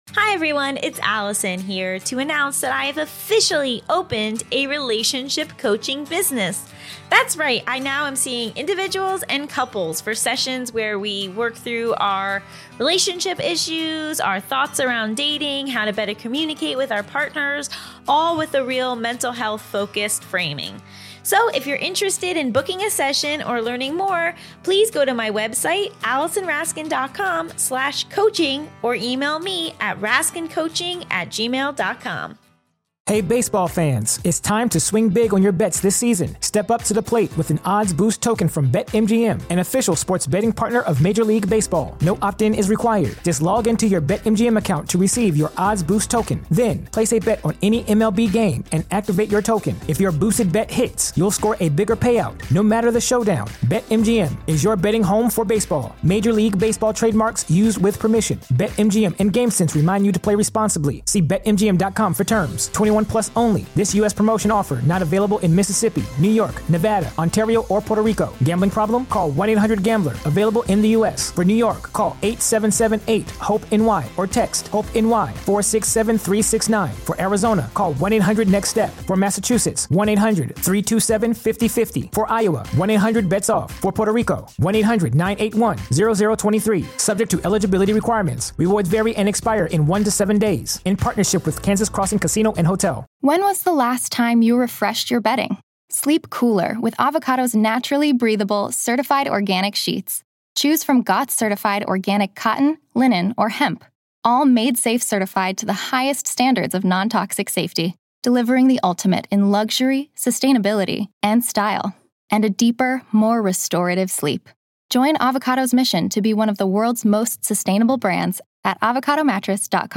Mental Health, Education, Sexuality, Comedy, Self-improvement, Relationships, Comedy Interviews, Society & Culture, Personal Journals, Health & Fitness